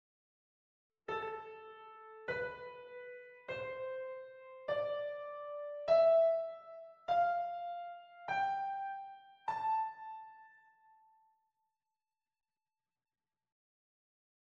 Aeolian Mode
The Aeolian Mode can be found by playing the white keys on the piano from A to A;
aeolian-mode.mp3